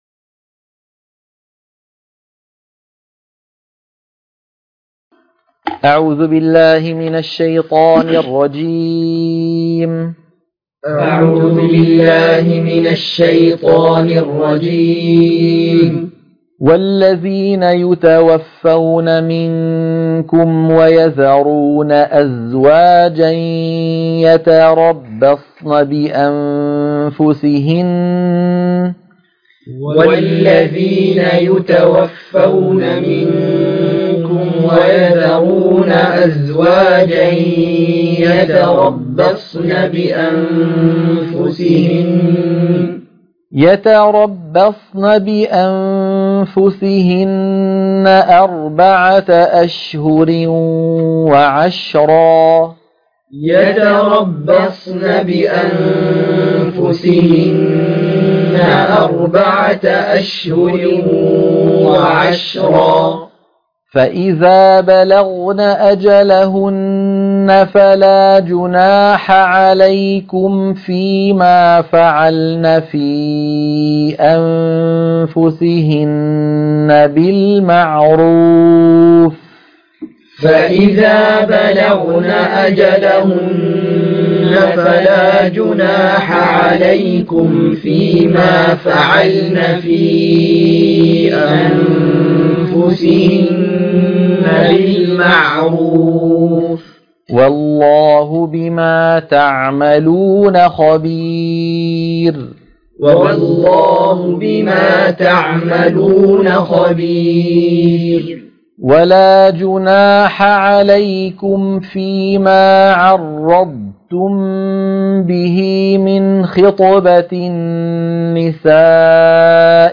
عنوان المادة تلقين سورة البقرة - الصفحة 38 التلاوة المنهجية